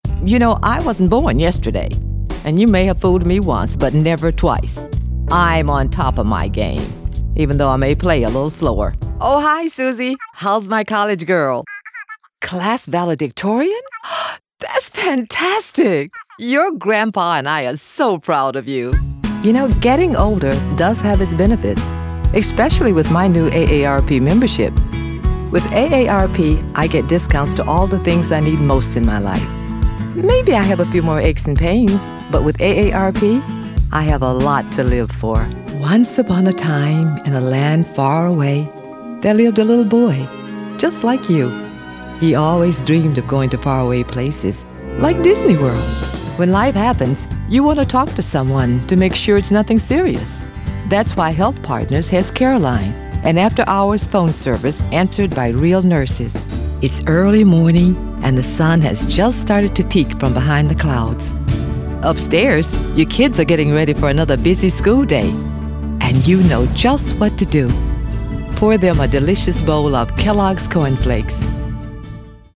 commercial : women